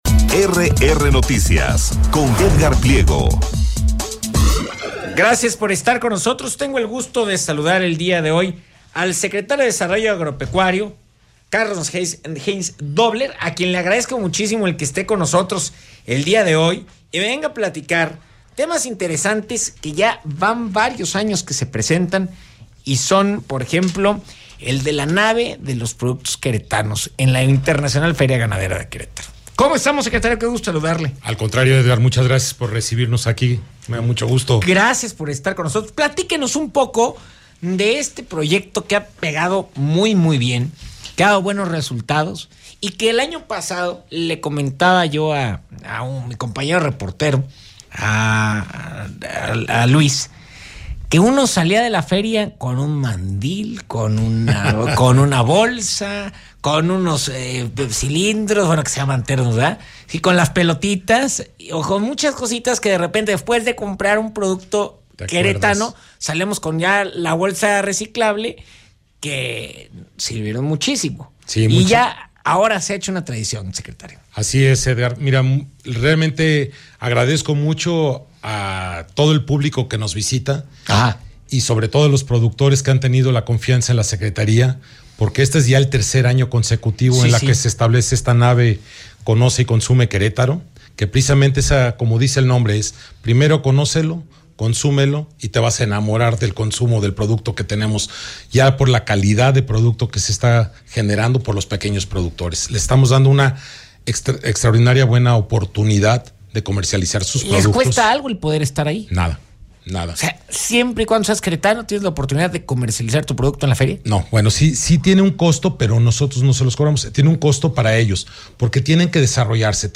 EntrevistasMultimediaPodcast
ENTREVISTA-CARL-HEINZ-DOBLER.mp3